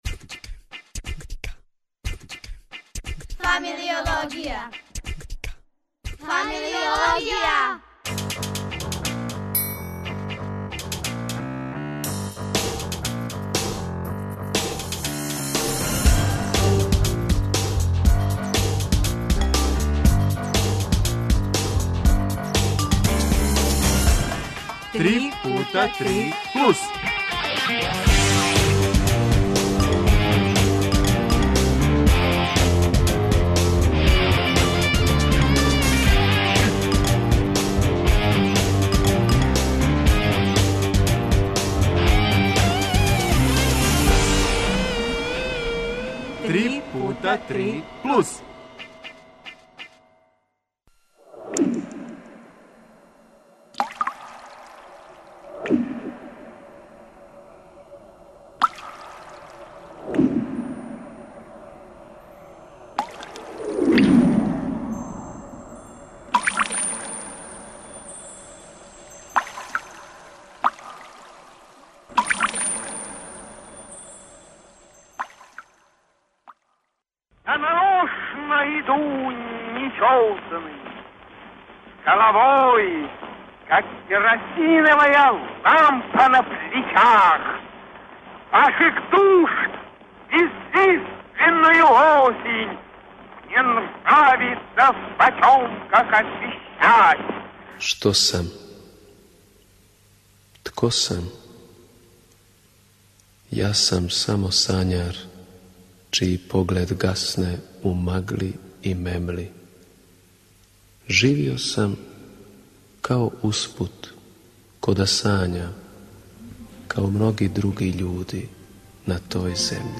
аутор и деца из дечје драмске групе